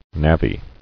[nav·vy]